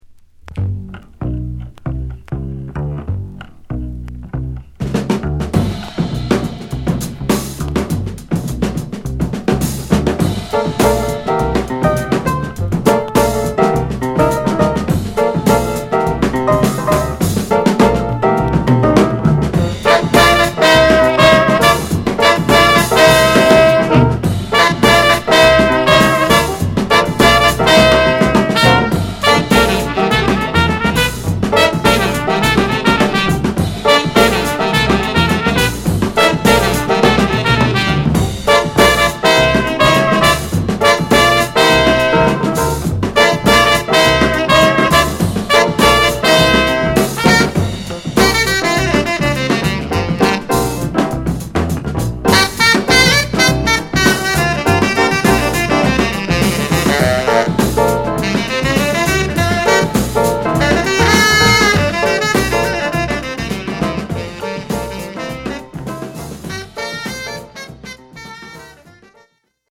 カリフォルニアの片田舎、ソノマ群に残された激レア・インディージャズ！
ハードに打ったドラムにファンキーなピアノとSaxが絡むキラーチューン！！